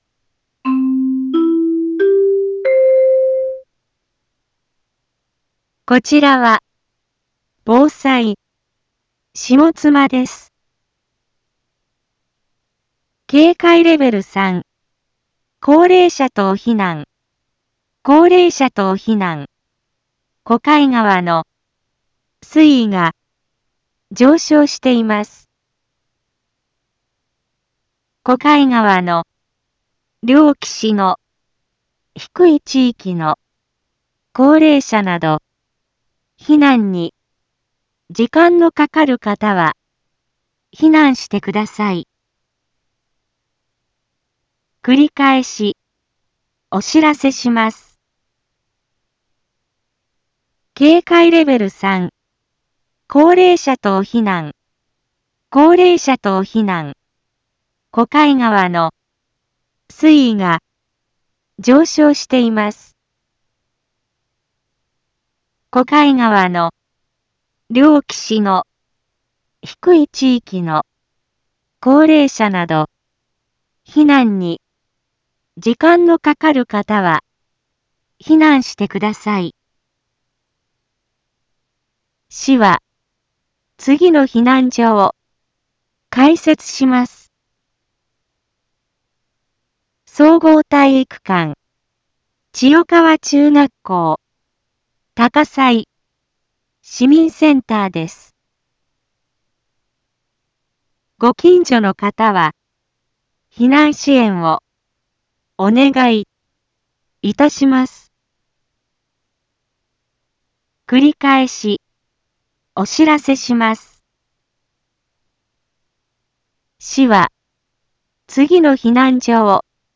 一般放送情報
Back Home 一般放送情報 音声放送 再生 一般放送情報 登録日時：2023-06-02 21:02:45 タイトル：高齢者避難の発令について インフォメーション：こちらは、防災、下妻です。